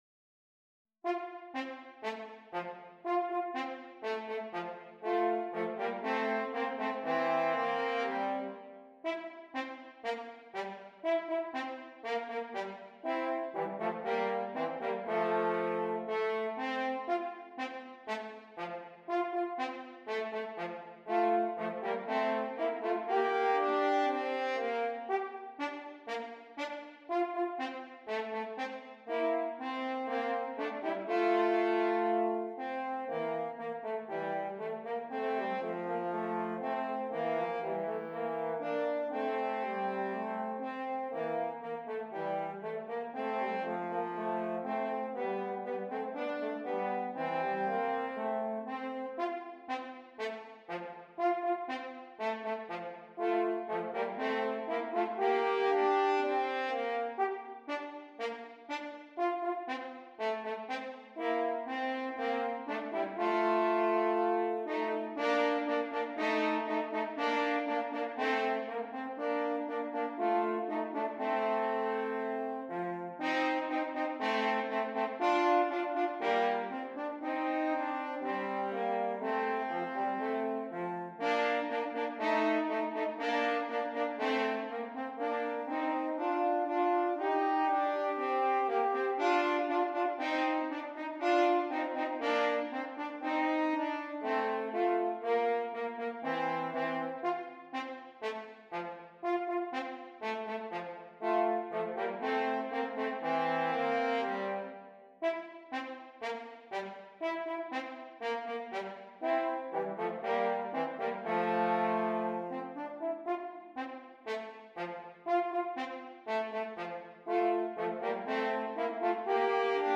2 F Horns